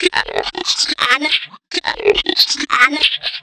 Index of /90_sSampleCDs/Sample Magic - Transmission-X/Transmission-X/transx loops - 140bpm